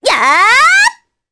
Reina-Vox_Attack4_jp.wav